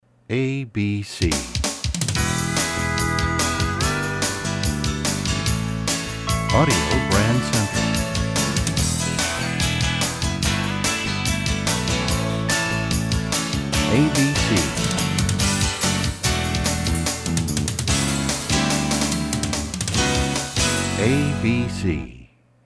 MCM Category: Radio Jingles
Genre: Jingles.